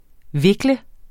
Udtale [ ˈveglə ]